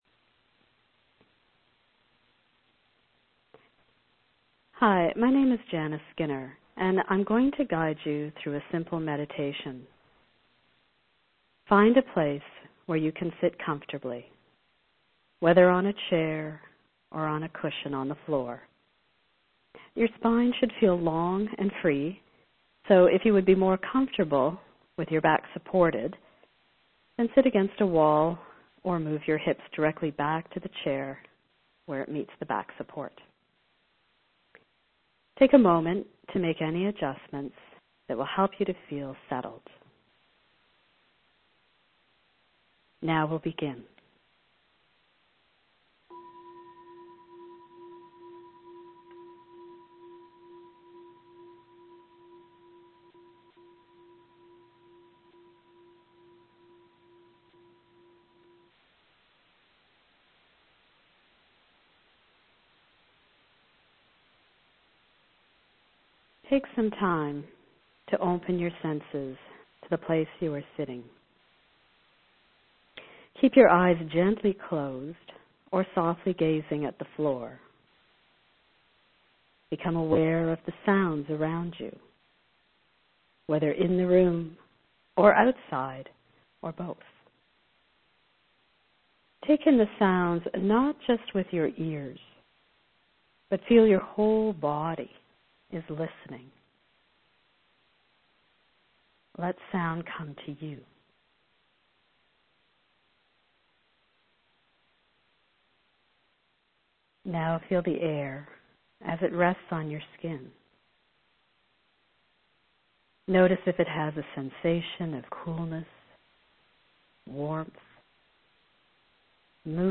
Please join me in the following 10-minute guided meditation practice: